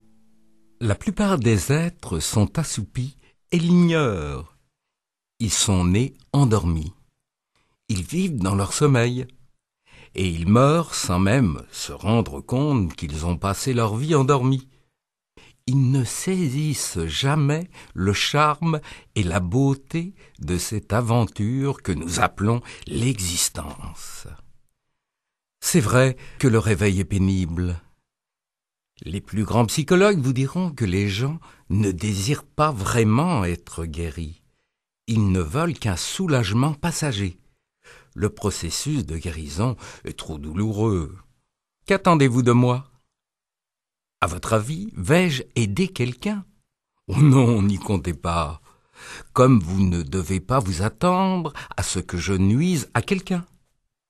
Lire un extrait - Quand la conscience s'éveille de Anthony De Mello